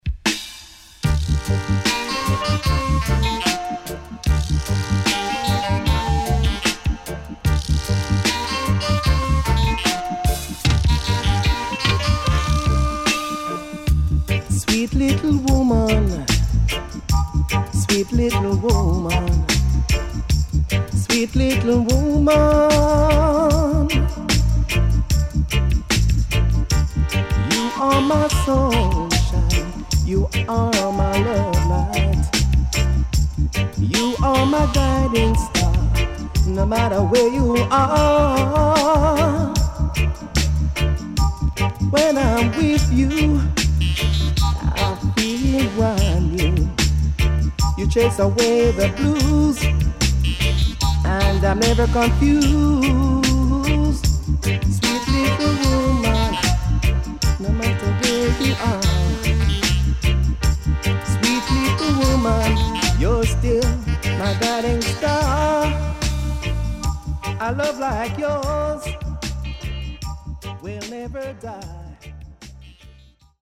83年マイナー調からGood Mediumまで良曲多数